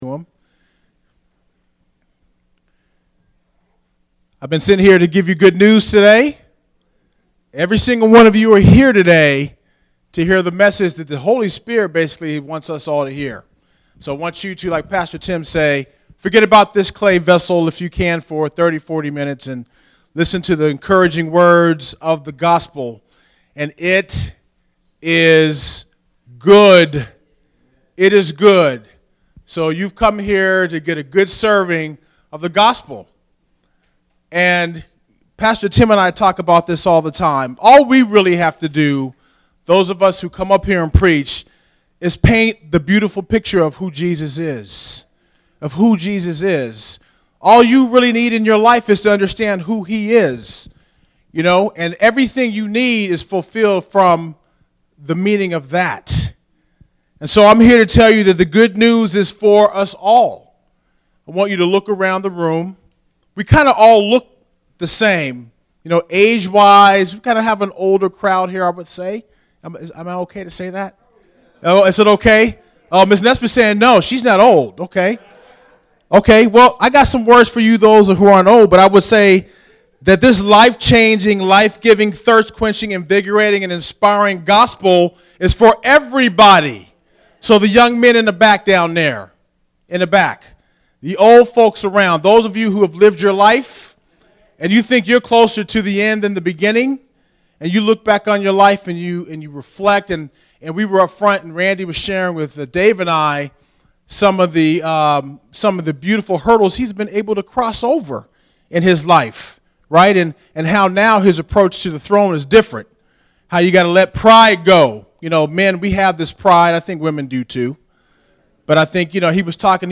In this Gospel Message